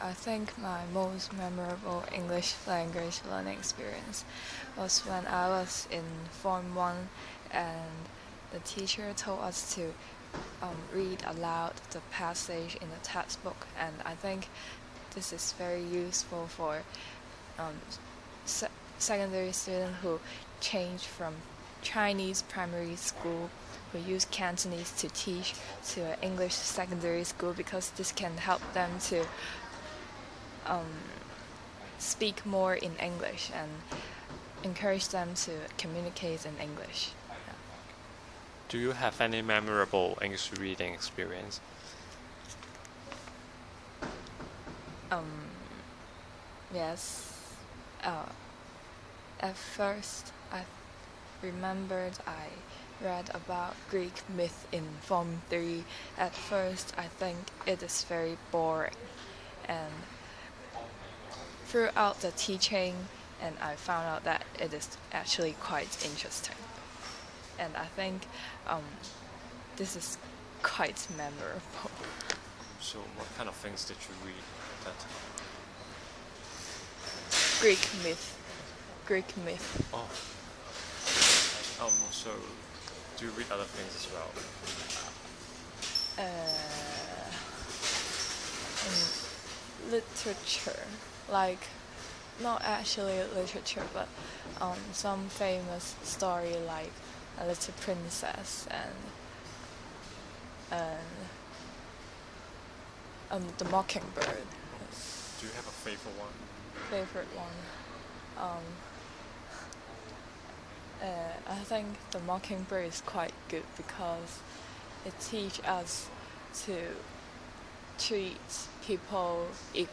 Subcategory: Fiction, Reading, Speech